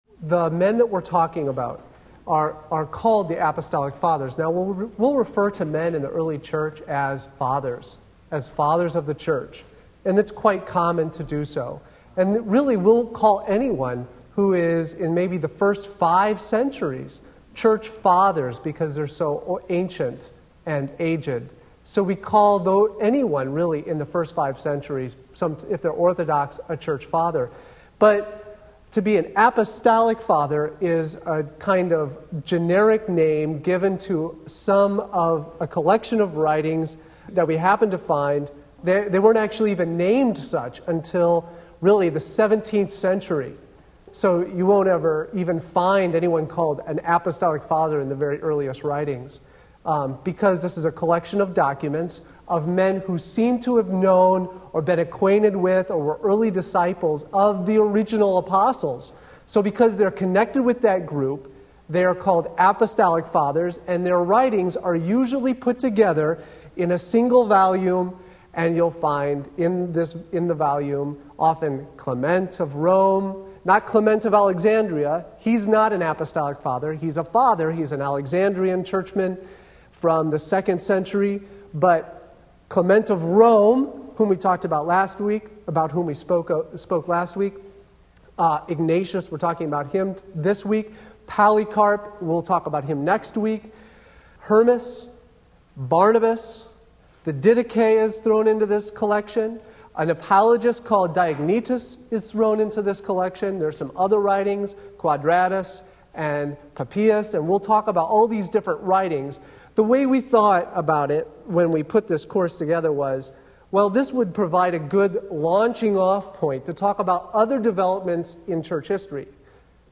Wednesday Evening Service